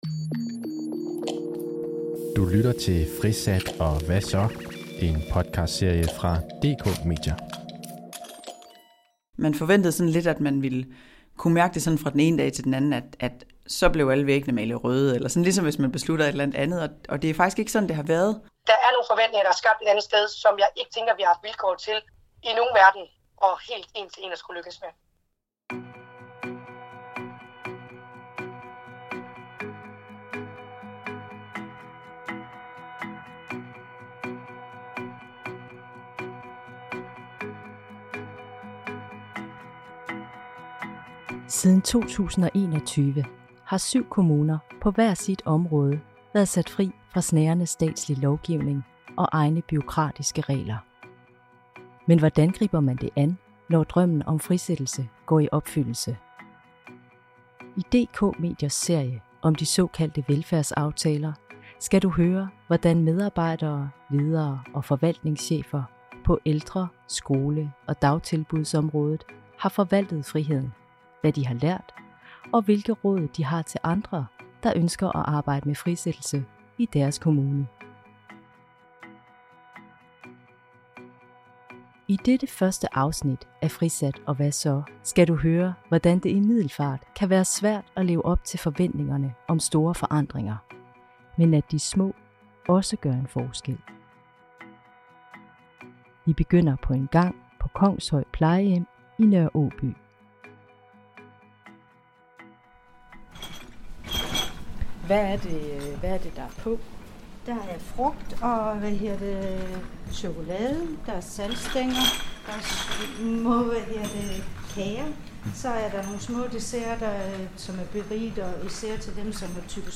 I DK Mediers serie om de såkaldte velfærdsaftaler kan du høre, hvordan medarbejdere, ledere og forvaltningschefer på ældre-, skole- og dagtilbudsområdet har forvaltet friheden - hvad de har lært, og hvilke råd de har til andre, der ønsker at arbejde med frisættelse i deres kommune.